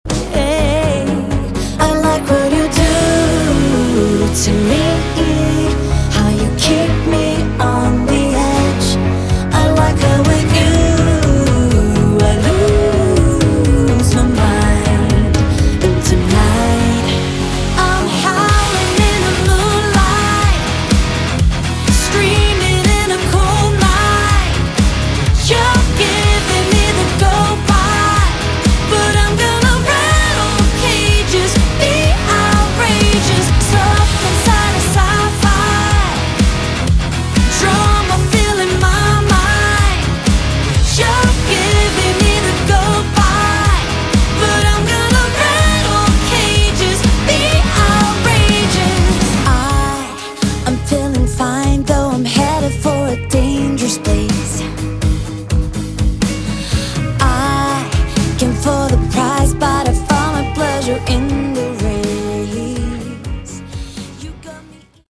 Category: Modern AOR
all instruments, backing vocals
lead vocals, backing vocals, piano